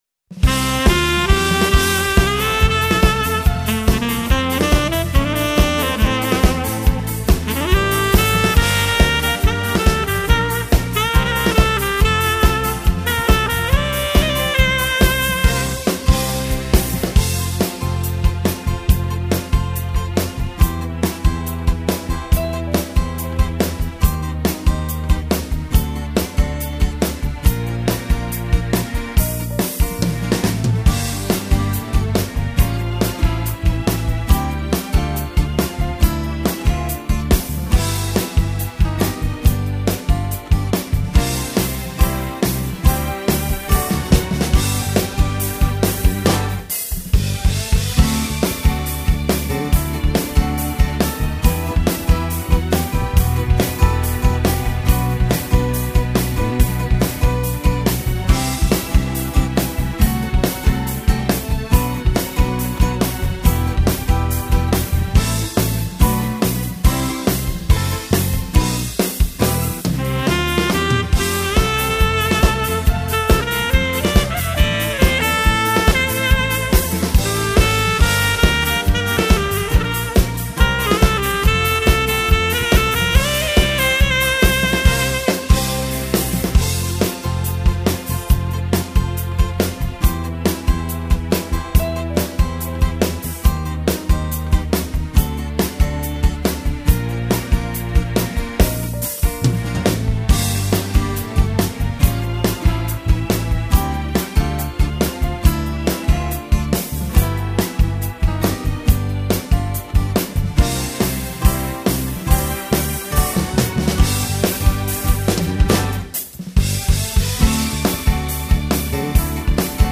strumental